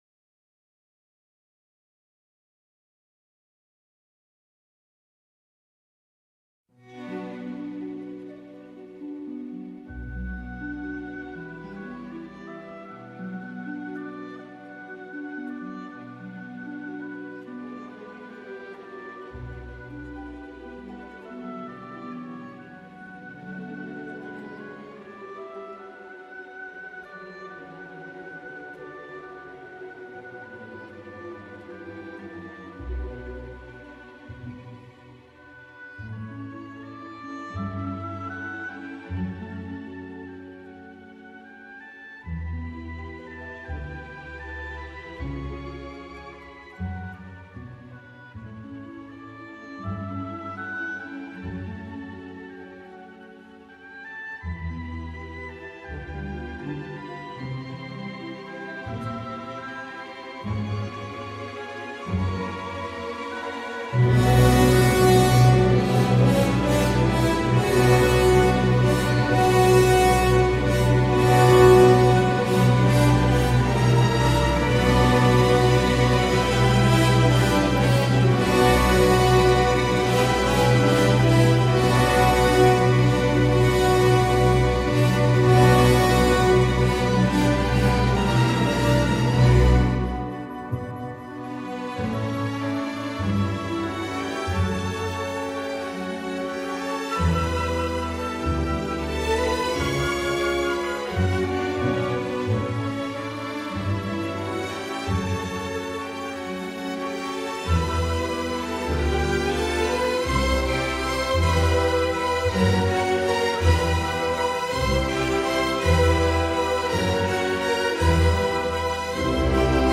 Classical, Ballet